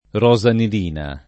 rosanilina [ r q@ anil & na ]